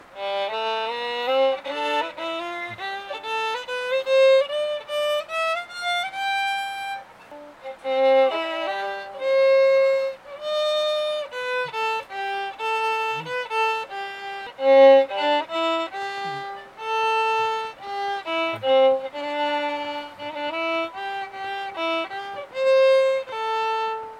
(no where near ideal) So I moved it 3mm back from the bridge and 3mm towards the bass bar, brightened the G and D right up.
Ok here is a sound file of the 600 after the soundpost move.
Hmmmm... how do I say this in lay-mans terminology - it sounds "cleaner" and a little more "lively".